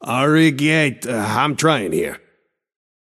Shopkeeper voice line - Arigeat… Uh, I’m tryin‘ here.